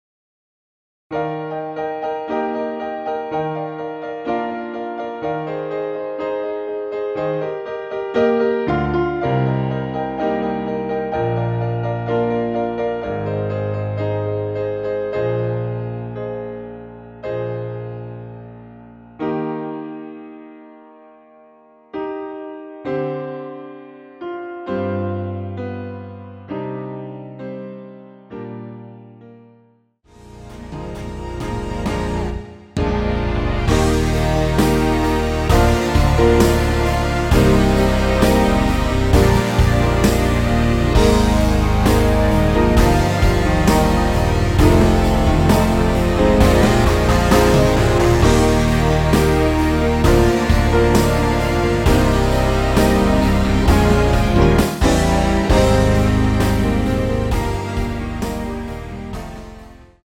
원키에서(-3)내린 MR입니다.
Eb
앞부분30초, 뒷부분30초씩 편집해서 올려 드리고 있습니다.